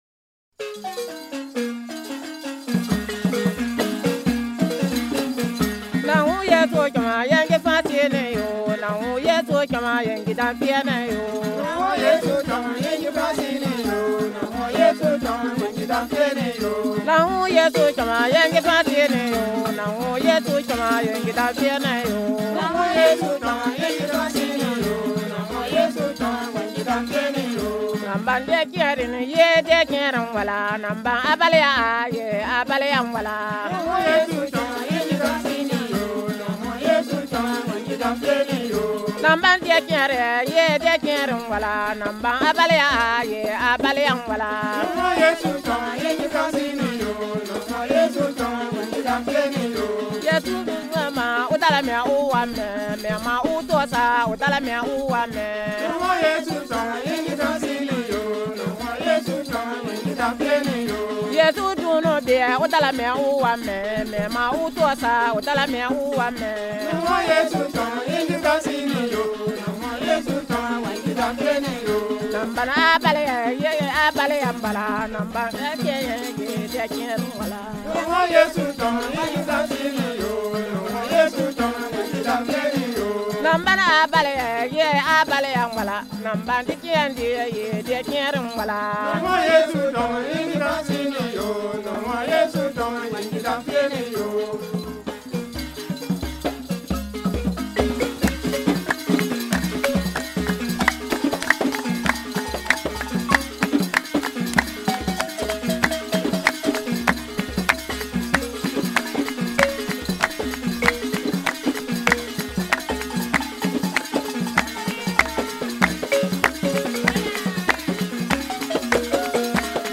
On this page, we offer you recordings of Turka praise songs recorded during a song writing workshop in Douna in 2015.